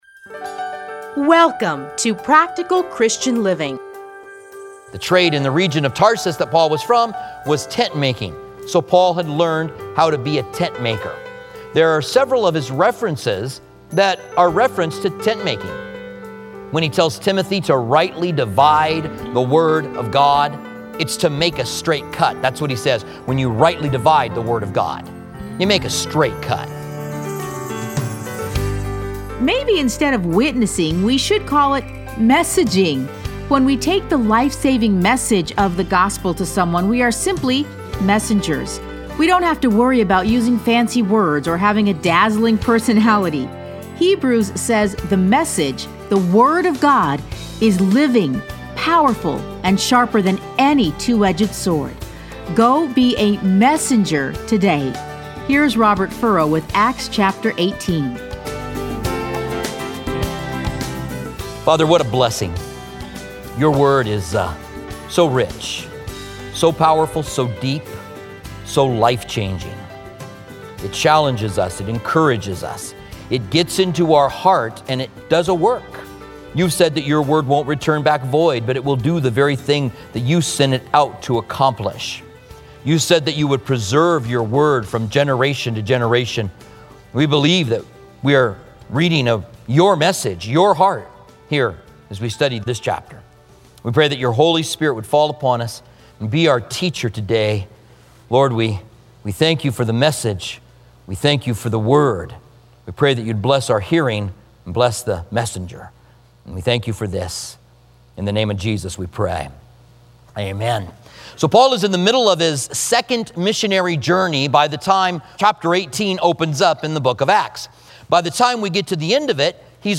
Listen to a teaching from Acts 18:1-28.